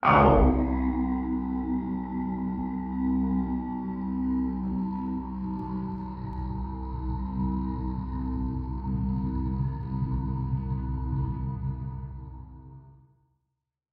Мелодия гармонии и умиротворения в медитации